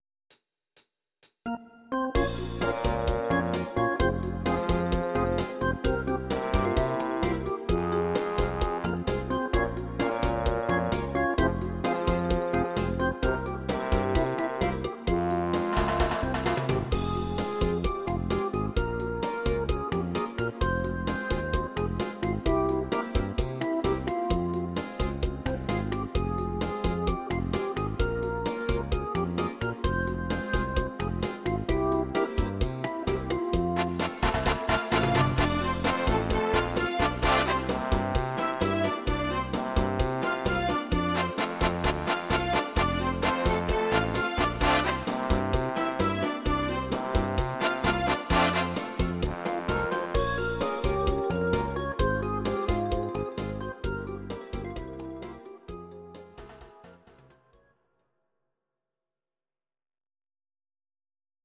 Audio Recordings based on Midi-files
Instrumental
instr. Orgel